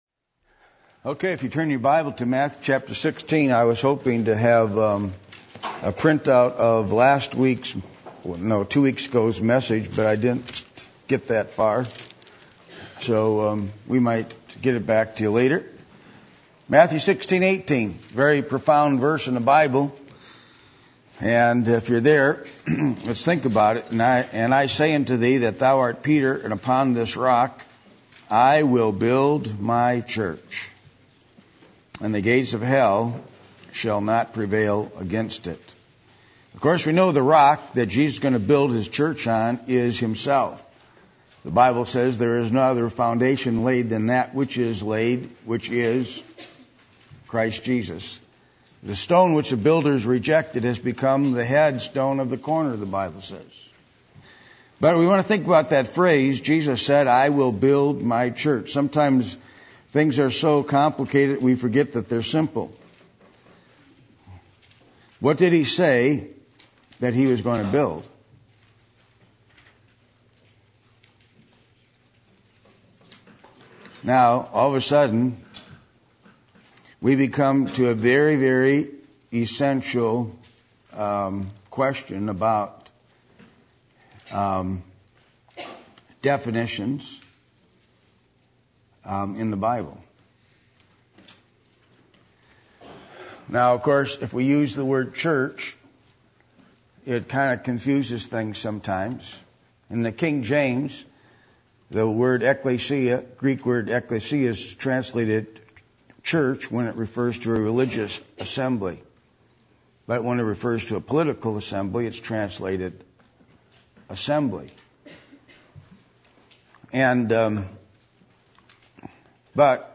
Matthew 16:18 Service Type: Midweek Meeting %todo_render% « How Is The Work Of The Lord Going To Get Done?